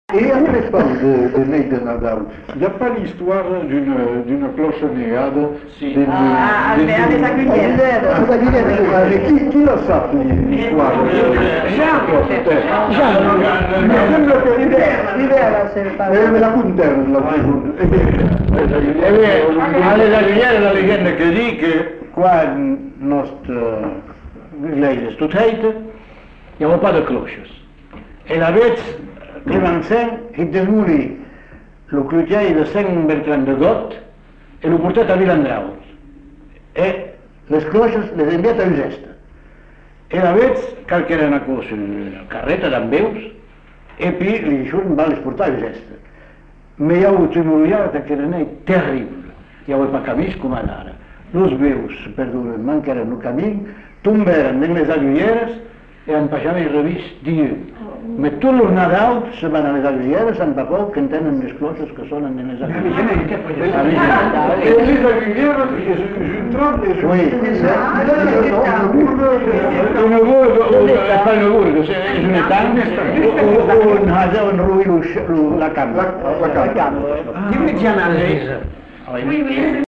Lieu : Uzeste
Genre : conte-légende-récit
Effectif : 1
Type de voix : voix d'homme
Production du son : parlé
Classification : récit légendaire